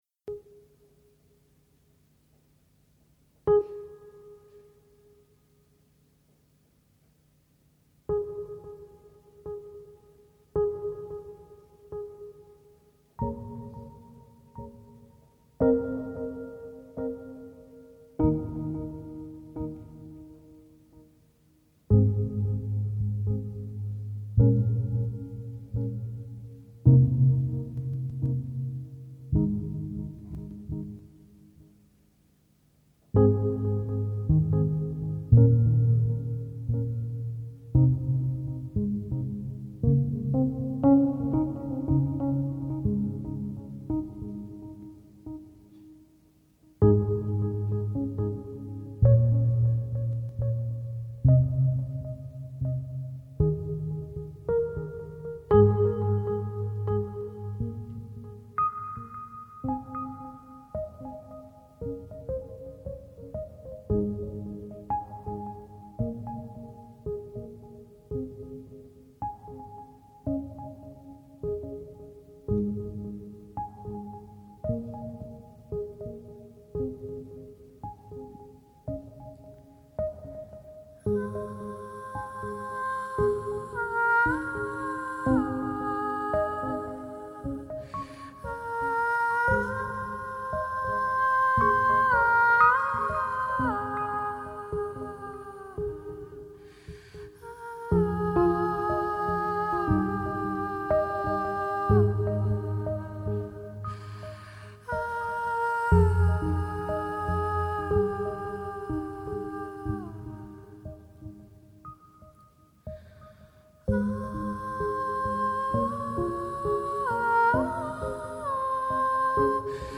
Styl: Hip-Hop
MP3 Stereo 44kHz 128Kbps (CBR)